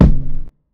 000_Lo-Fi Old Kick.1.L.wav